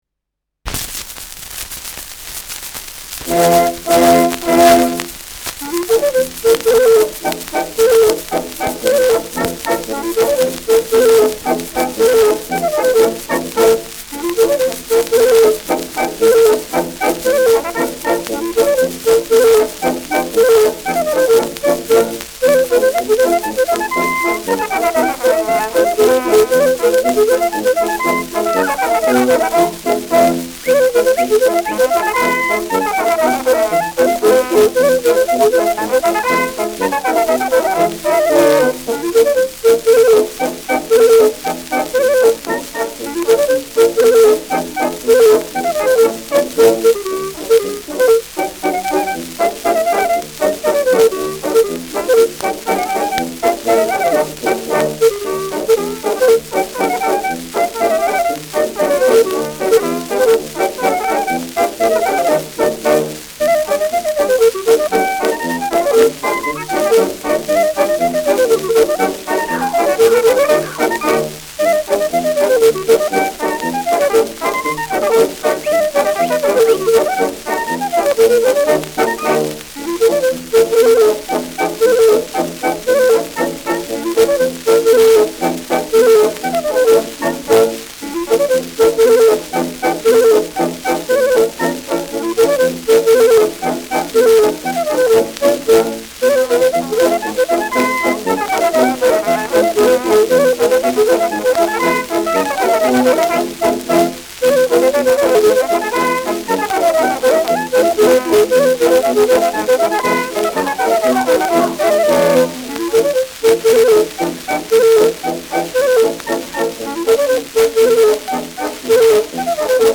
Klarinettenschottisch
Schellackplatte
präsentes Rauschen